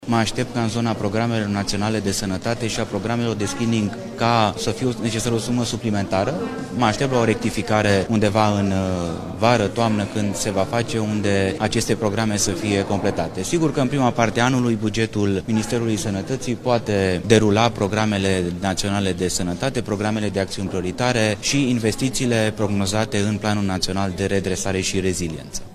De exemplu, ministrul Sănătății a explicat în Parlament că printre acestea se află Programele Naționale de Screening și Prevenție pentru neonatologie, cancerul de sân, cancerul de col uterin și cancerul colorectal.
Ministrul Sănătății, Alexandru Rogobete: „Mă aștept ca, în zona programelor naționale de sănătate și a programelor de screening, să fie necesară o sumă suplimentară”